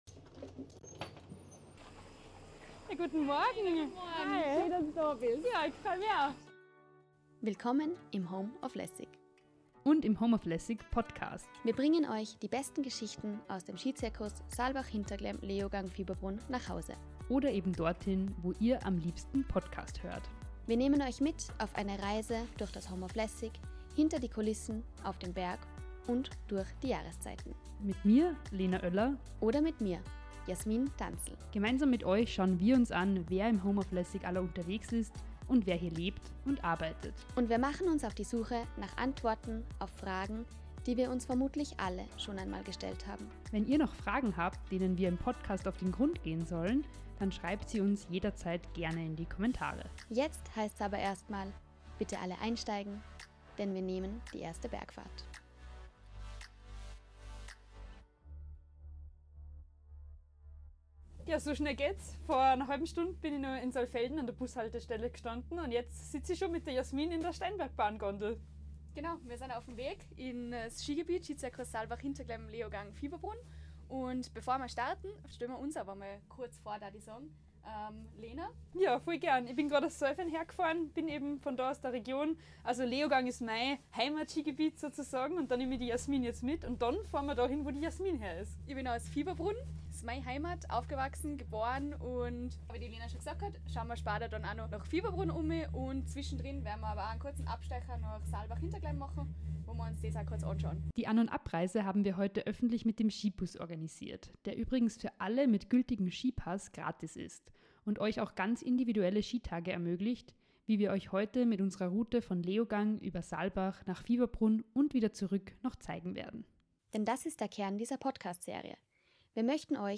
Eine Folge voller Bergpanorama und echter Begegnungen.